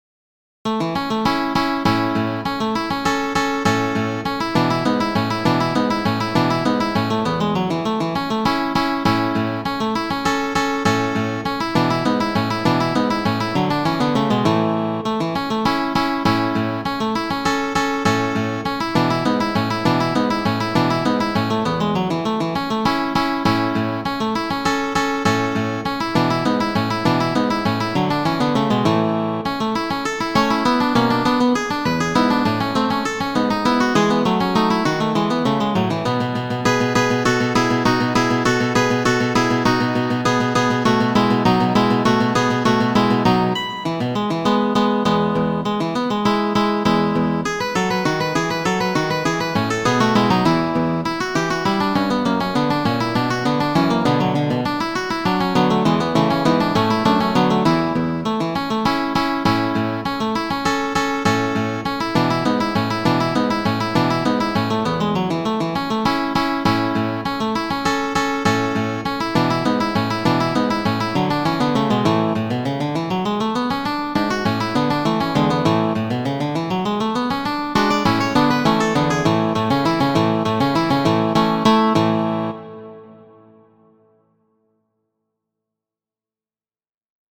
Kajero 29ª ~ En PDF (paĝo 2ª) ~ Aliaj Kajeroj Depósito legal: Z-2398-88 Presejo: Eurocopia, S.L. Muziko: Menueto, verko 11-5 de Fernando Sor.